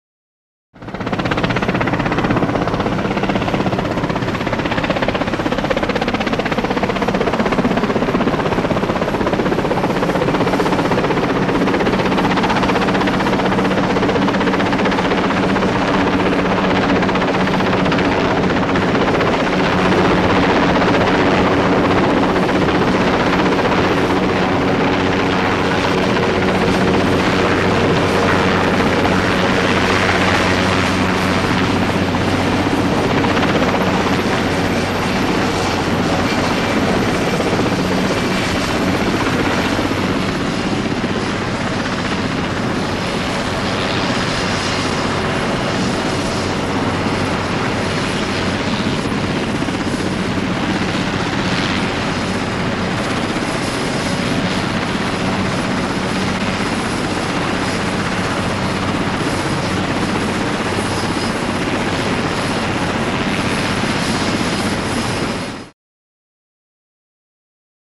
Helicopter; Hover; Wessex Helicopter Approach And Hover Just Off Mic.